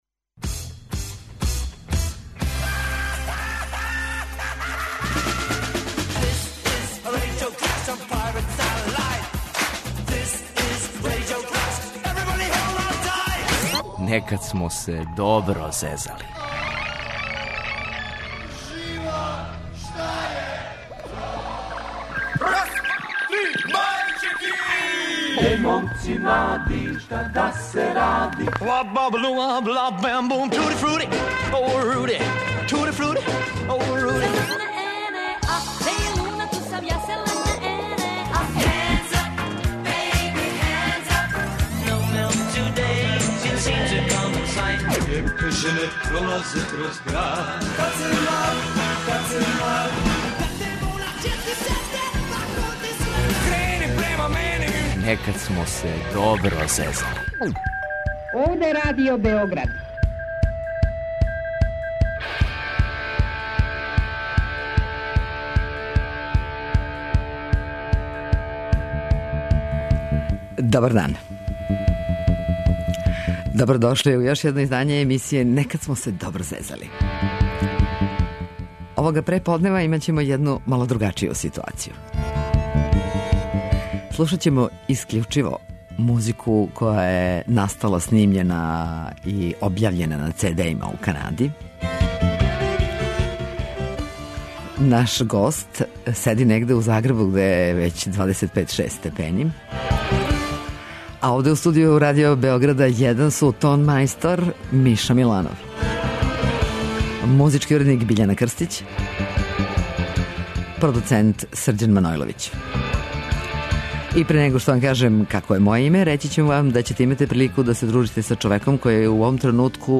Слушаћемо добар rock and roll из ове северноамеричке земље.